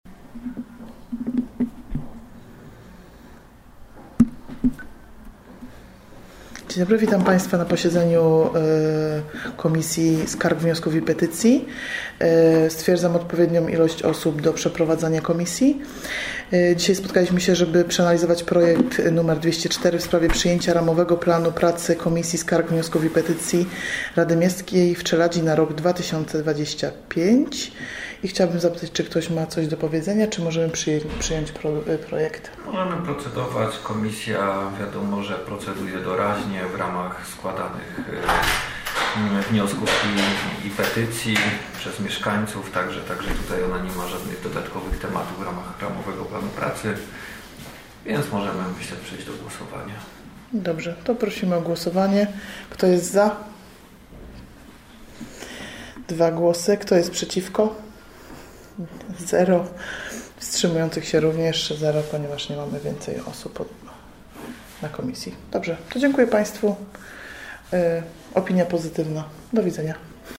Tytuł dokumentu Posiedzenie Komisji Skarg, Wniosków i Petycji w dniu 9 grudnia 2024 r.